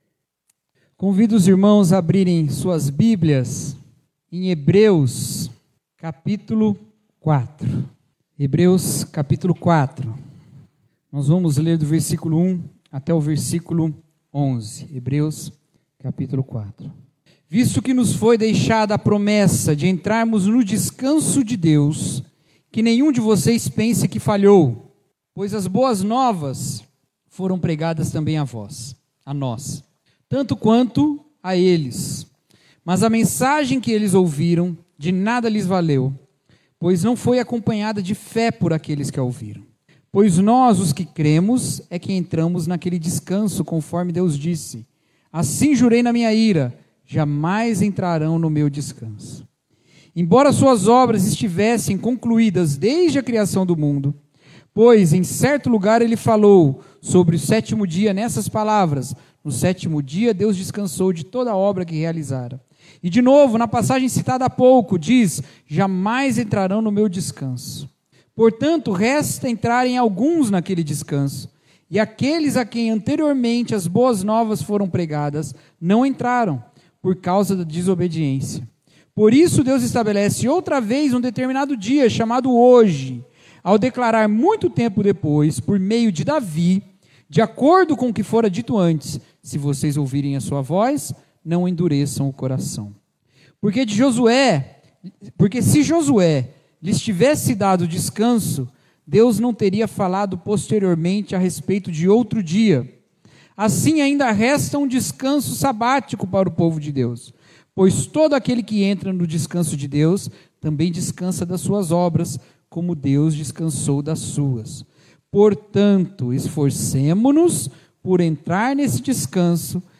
[Série: Supremo Cristo] Mensagem – Obedecer é Descansar